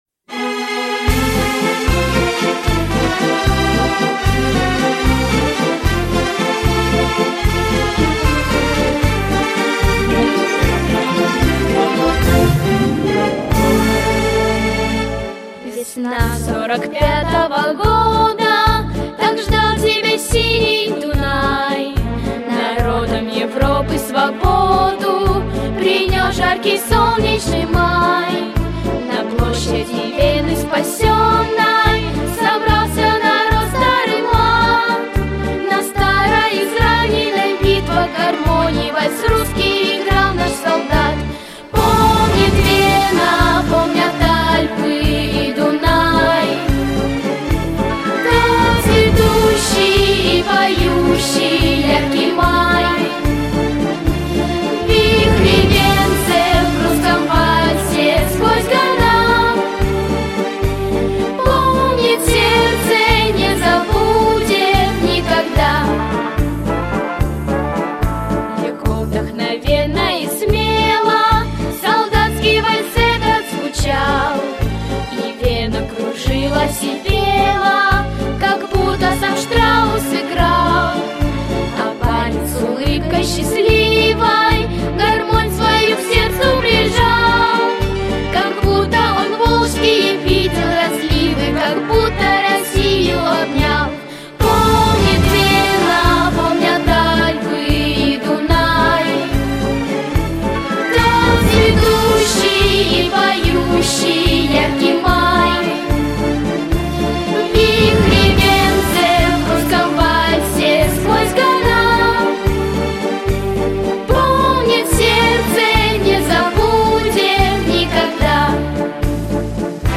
• Качество: Хорошее
• Жанр: Детские песни
военные песни